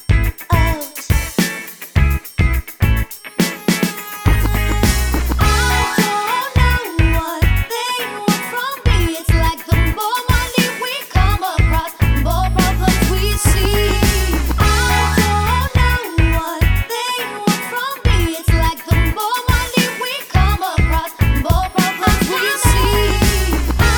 for solo male R'n'B